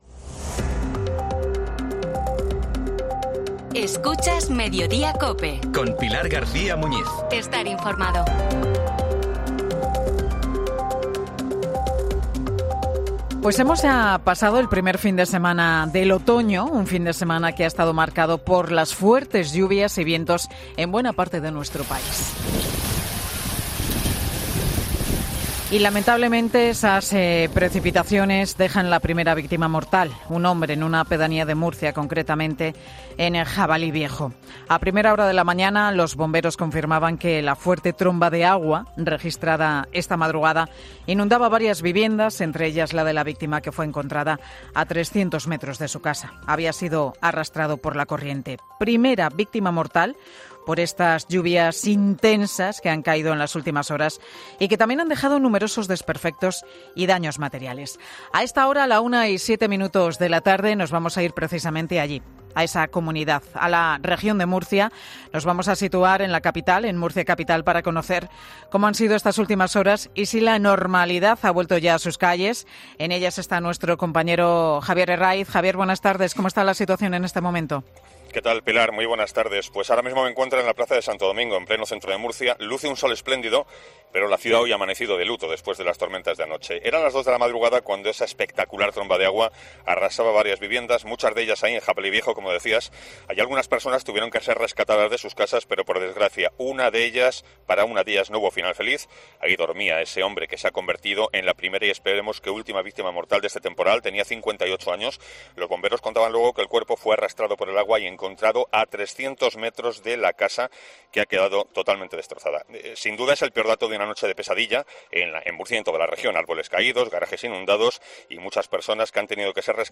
Como cuentan los compañeros de COPE Murcia desde la calle, la situación está más calmada al mediodía, pero se teme por lo que pueda pasar en las próximas horas.